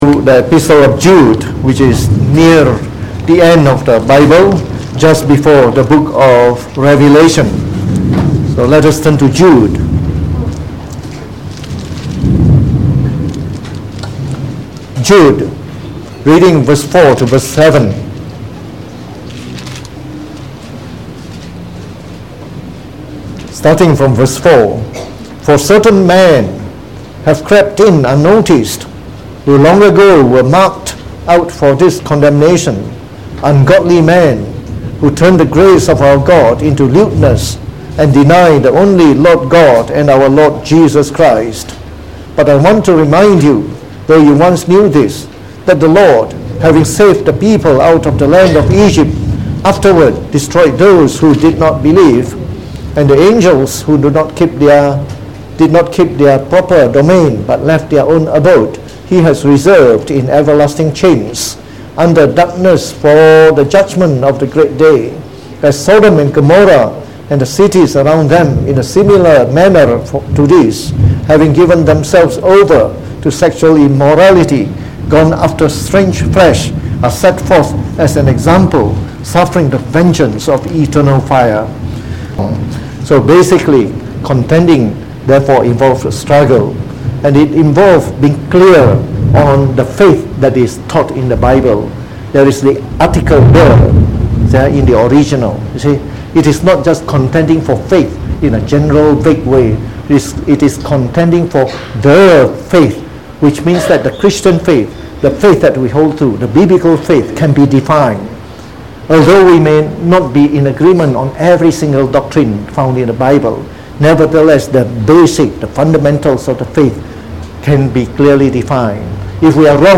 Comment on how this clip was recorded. Preached on the 22nd of Dec 2019.